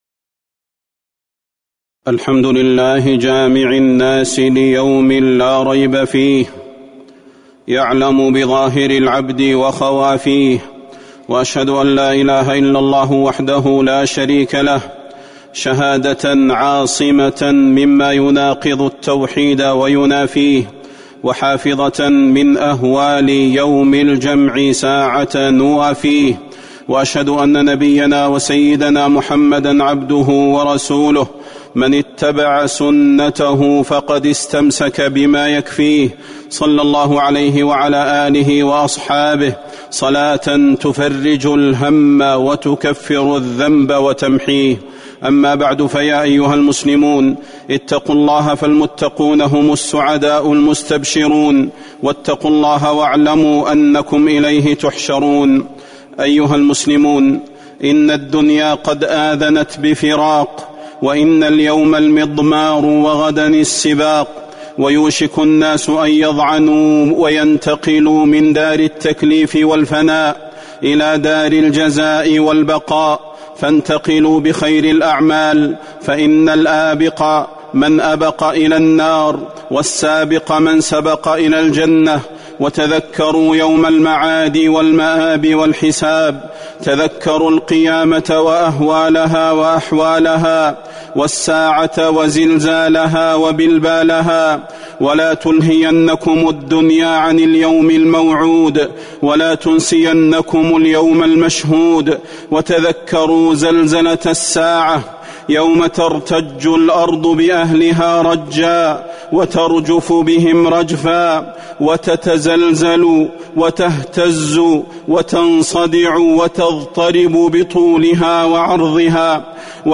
تاريخ النشر ١٣ جمادى الأولى ١٤٤٣ هـ المكان: المسجد النبوي الشيخ: فضيلة الشيخ د. صلاح بن محمد البدير فضيلة الشيخ د. صلاح بن محمد البدير اليوم الحق